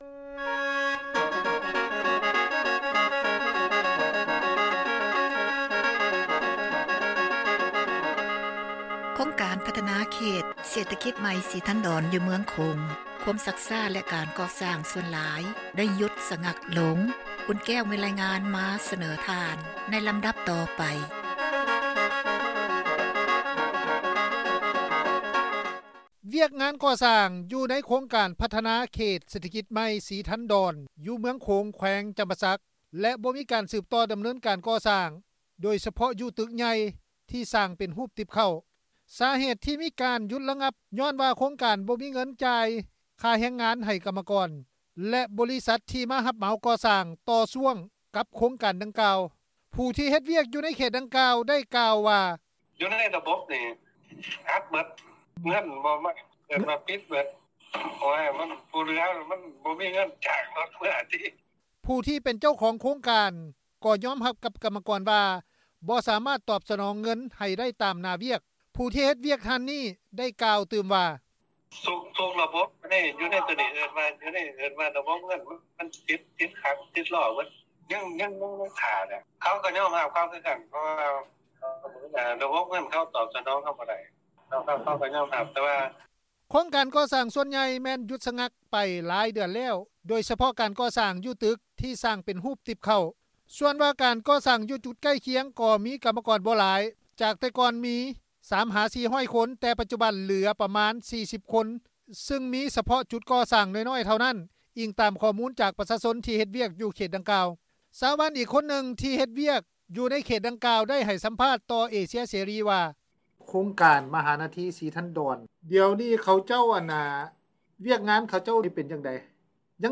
ຊາວບ້ານອີກຄົນນຶ່ງ ທີ່ເຮັດວຽກຢູ່ໃນເຂດດັ່ງກ່າວ ໄດ້ໃຫ້ສໍພາດຕໍ່ວິທຍຸເອເຊັຽເສຣີ ວ່າ: